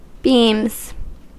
Ääntäminen
Vaihtoehtoiset kirjoitusmuodot (vanhahtava) beames Ääntäminen US Tuntematon aksentti: IPA : /biːmz/ Haettu sana löytyi näillä lähdekielillä: englanti Käännöksiä ei löytynyt valitulle kohdekielelle.